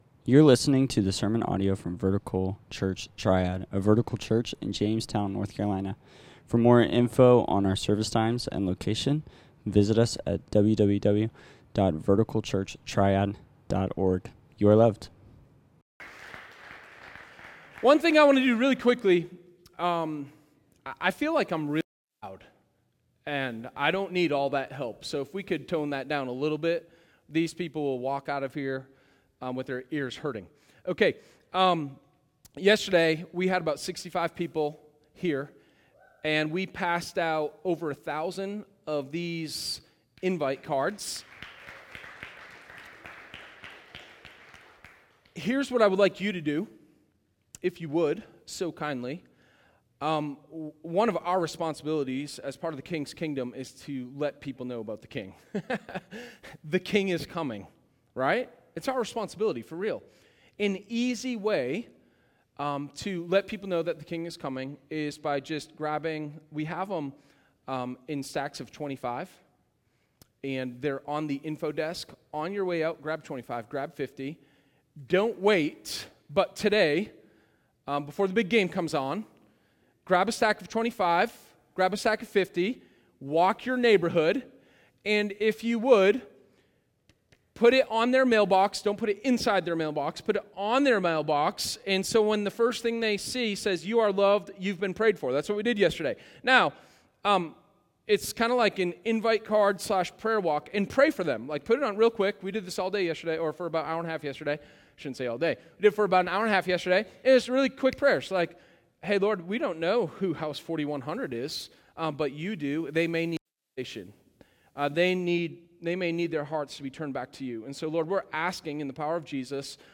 Sermon03_27_I_Am_The_Door.m4a